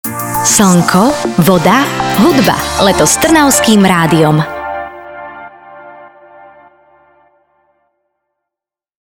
Kampaň je podporená jinglami vo vysielaní.